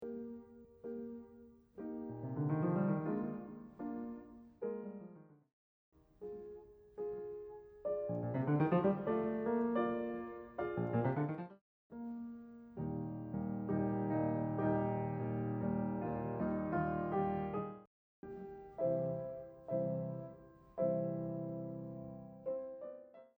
It has been played in the same dynamics, all times soft, in piano, but there has still been an amazing variation in character.
Here are the four different ways of the short-short-long motive, played in order: